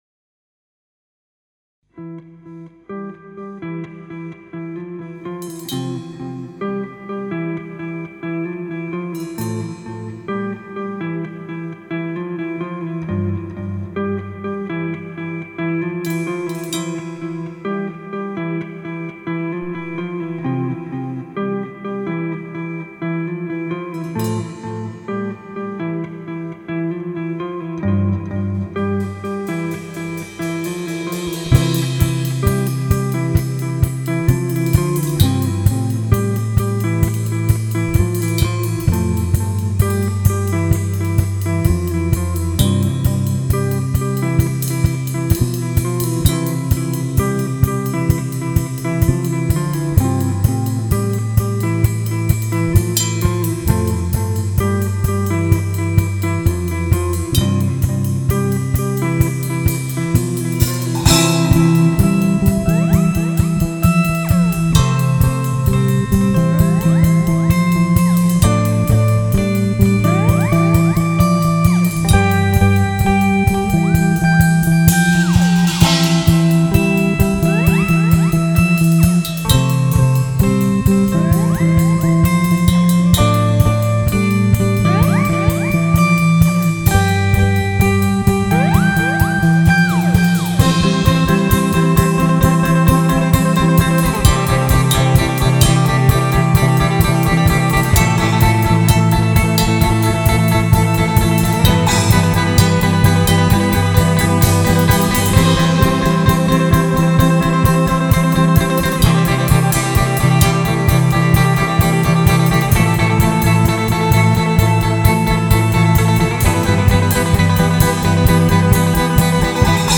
post rock
Chitarra/Tastiera
Basso
Batteria